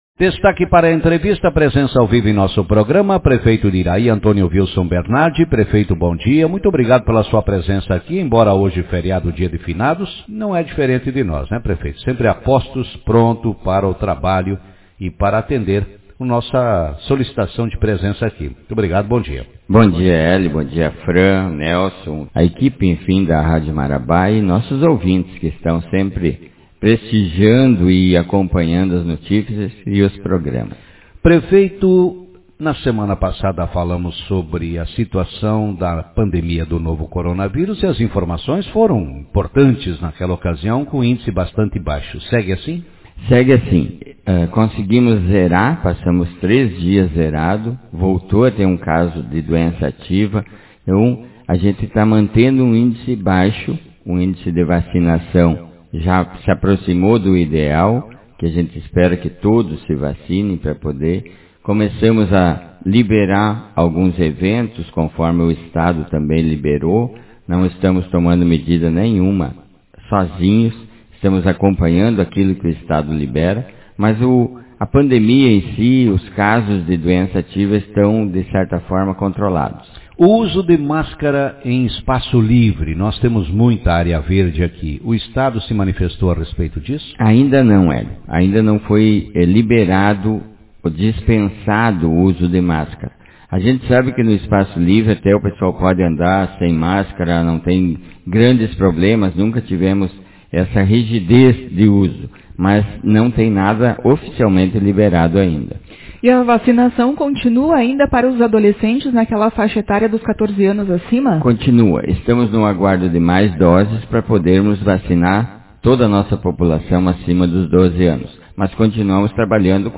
Prefeito de Iraí fala das atividades na saúde, obras e educação Autor: Rádio Marabá 02/11/2021 Manchete Na manhã desta terça-feira no programa Café com Notícias, o Prefeito de Iraí, Antonio Vilson Bernardi, falou sobre as atividades que estão sendo realizadas nas pastas da saúde, obras e educação no município.